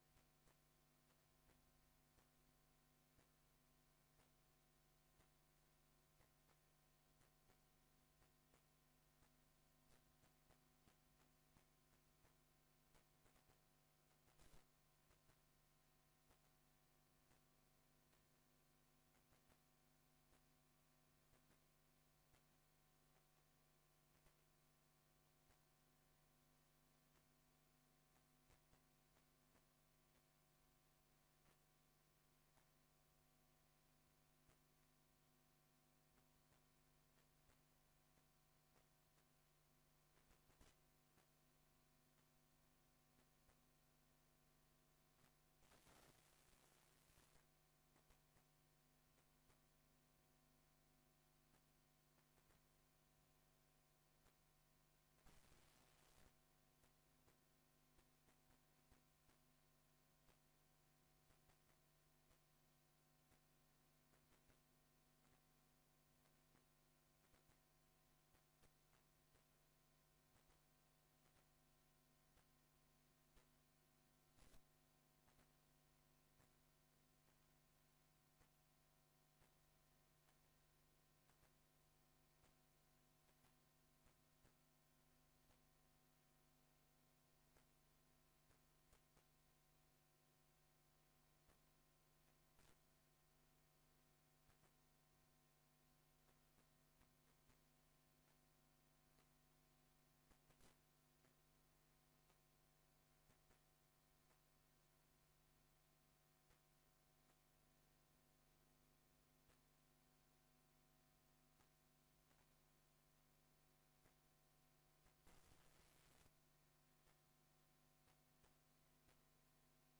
Download de volledige audio van deze vergadering
Locatie: Raadszaal